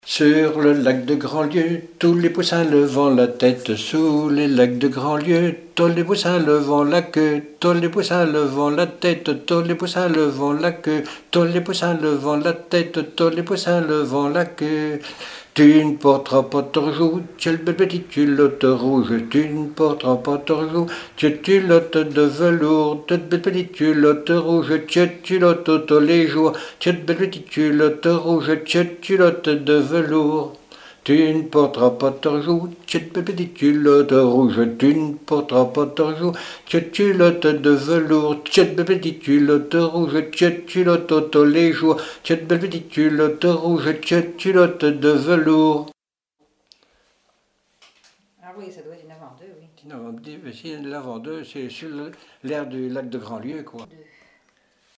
Divertissements d'adultes - Couplets à danser
branle : courante, maraîchine
Répertoire de chants brefs pour la danse
Pièce musicale inédite